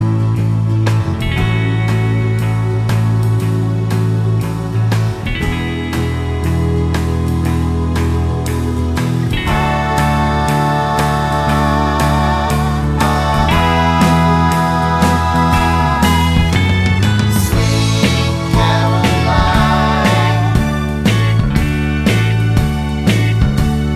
With No Backing Vocals Pop (1970s)